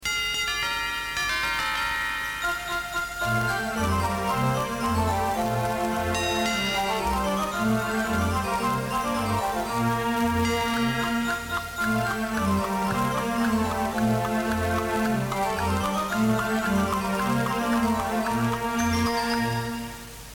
Indicatiu nadalenc instrumental